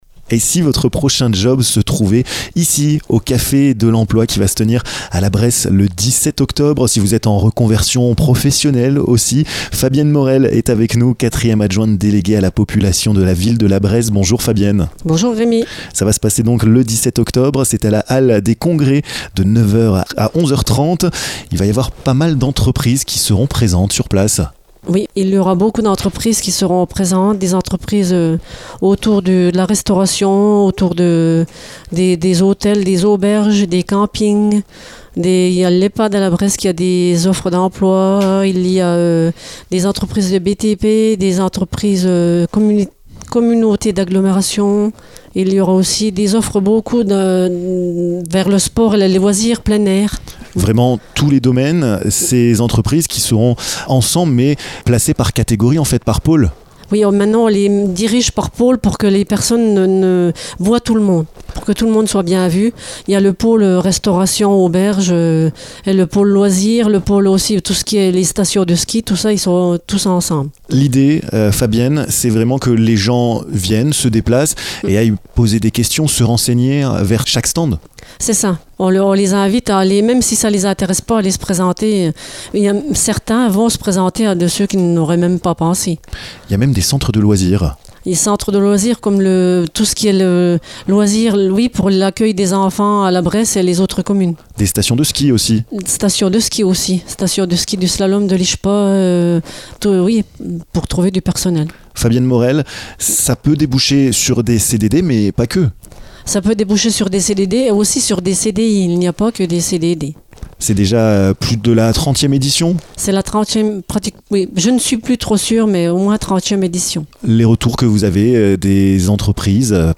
Fabienne Morel, 4e adjointe déléguée à la population, vous donnes toutes les explications dans ce podcast.